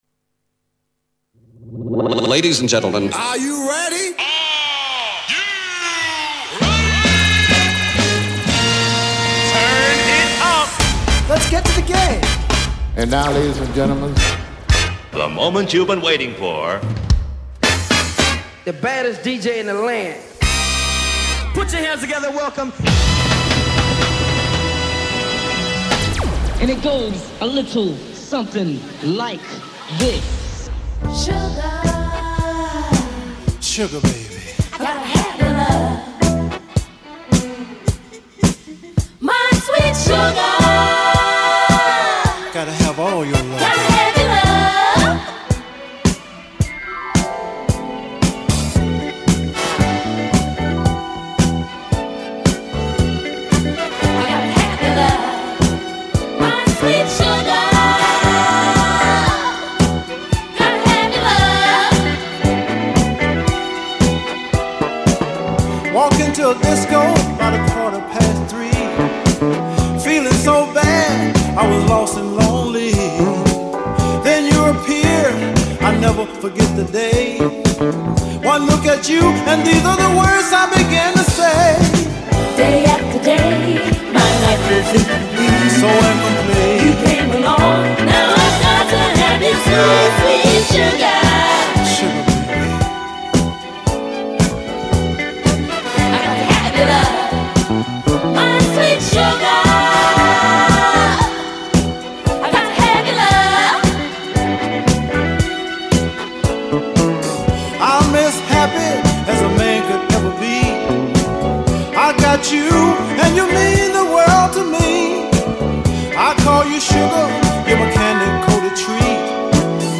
FUNKY HOME STUDIO